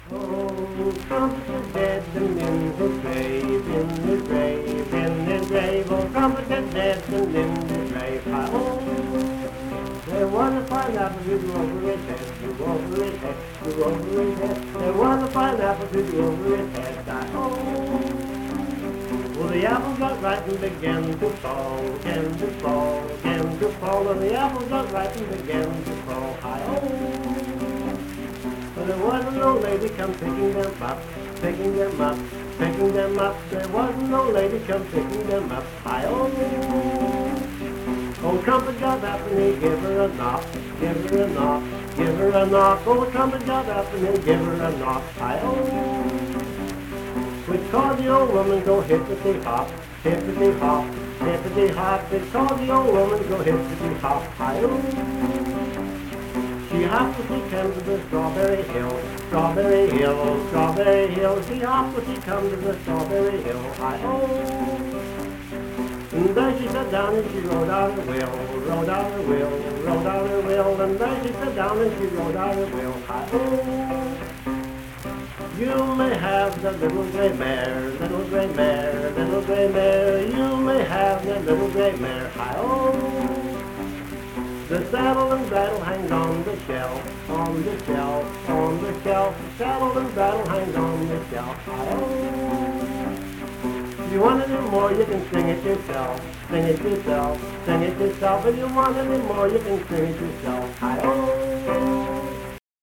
Accompanied vocal and guitar music
Performed in Hundred, Wetzel County, WV.
Dance, Game, and Party Songs
Voice (sung), Guitar